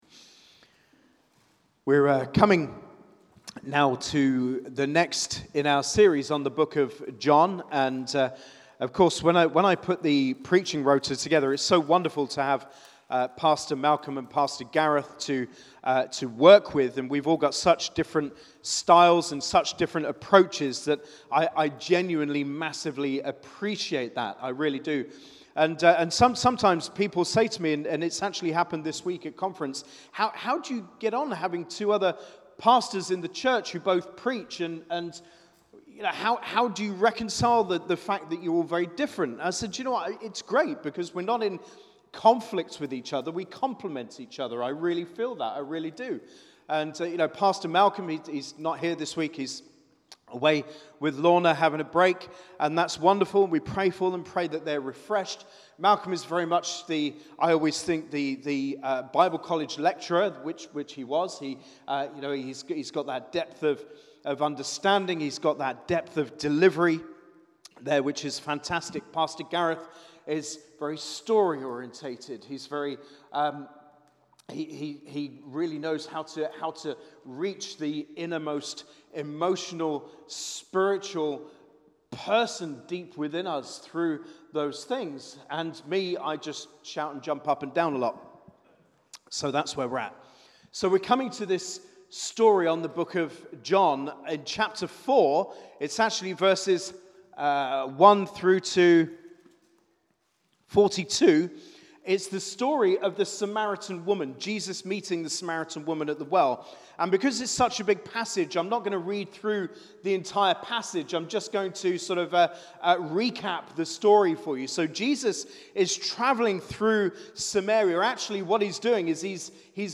Sermon - John 4:1-42
Watch the live recording from our Sunday service.